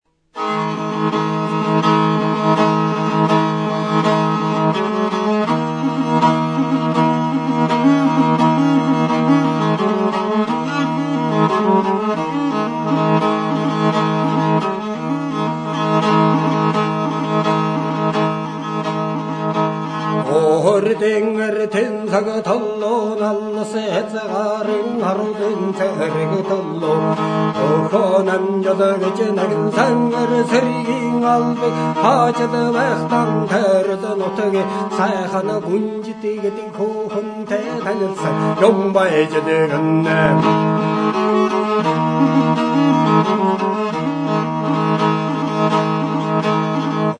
MORIN KHUUR; MORIN KHOOR | Soinuenea Herri Musikaren Txokoa
Bi sokazko biolontxelo motako soinu-tresna da.
Igurtzitzeko arkua du.